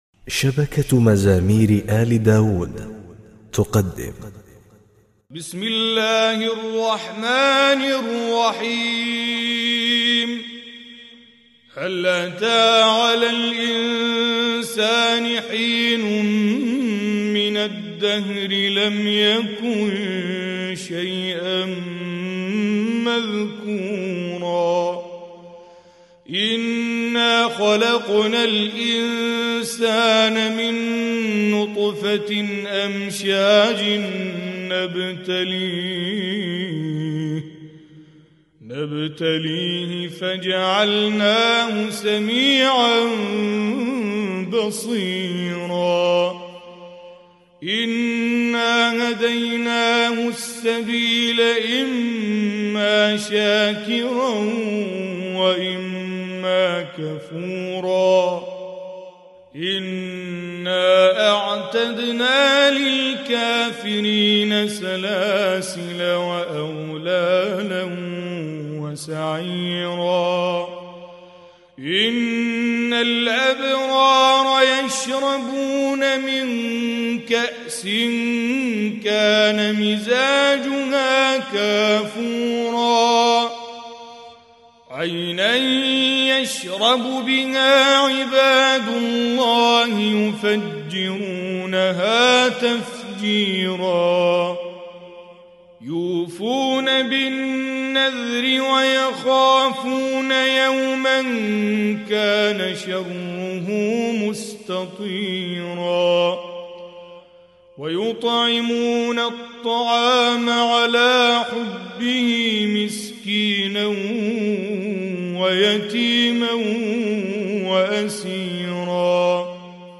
بجودة عالية